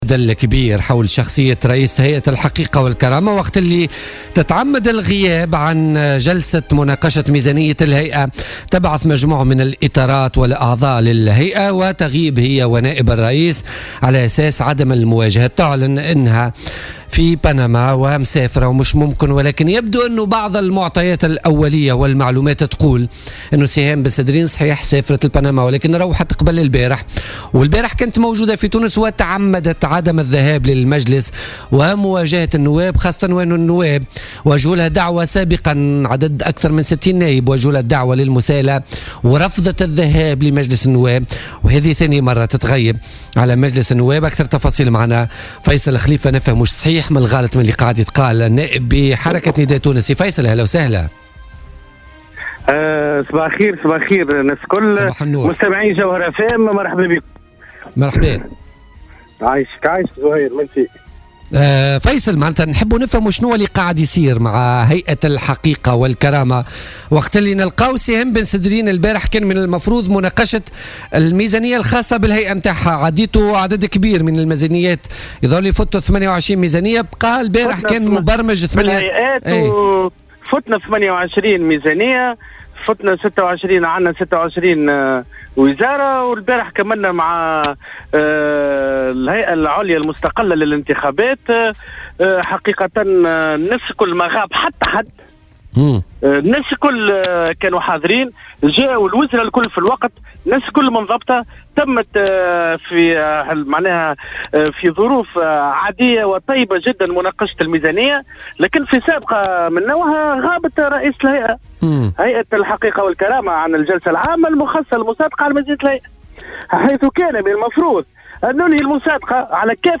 أكد النائب فيصل خليفة النائب بحركة نداء تونس في مداخلة له في بوليتيكا اليوم الثلاثاء 6 ديسمبر 2016 أن سهام بن سدرين رئيسة هيئة الحقيقة والكرامة تعمدت عدم حضور جلسة أمس لمناقشة الميزانية الخاصة بهيئتها.